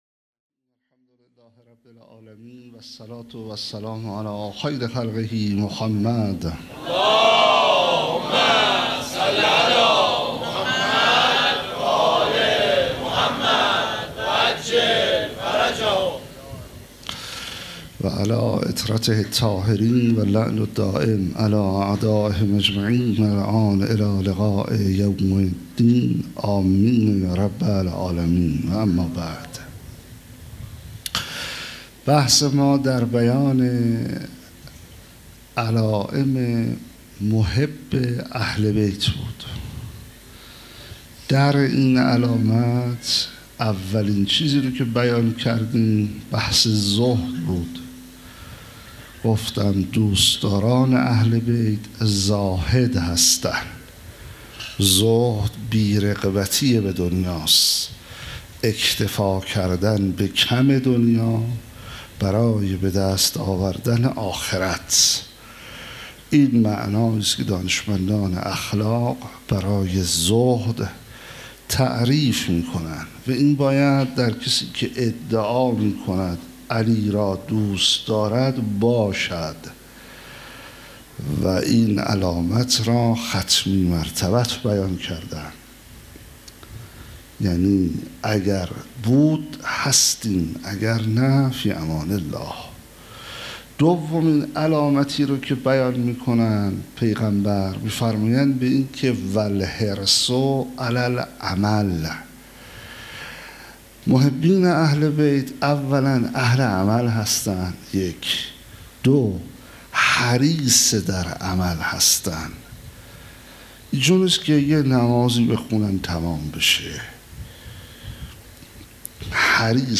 18 بهمن 97 - هیئت ریحانه الحسین - سخنرانی